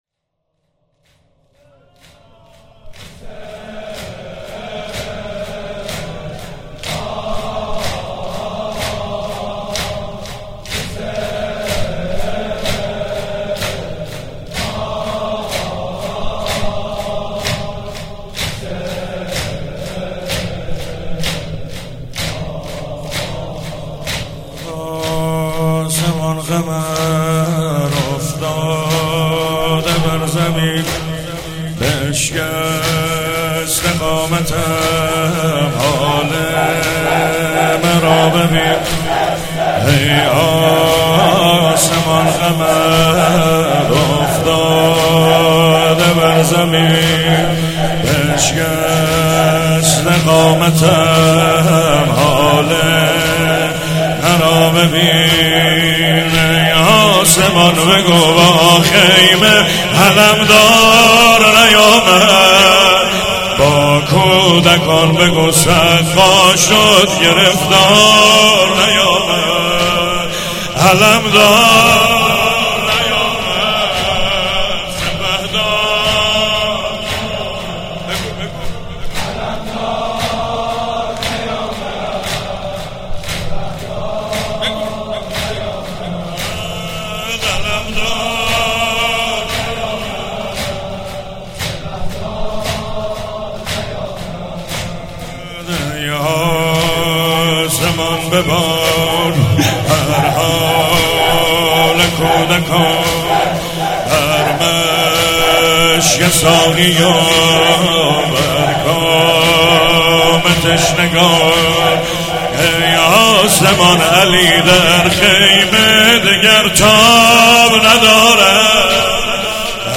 چهاراه شهید شیرودی حسینیه حضرت زینب (سلام الله علیها)
روضه حضرت ابالفضل(ع)